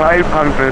IDG-A32X/Sounds/GPWS-OLD/altitude-500.wav at 7120ba3afed7e4a871db518fd3e111315cd095c2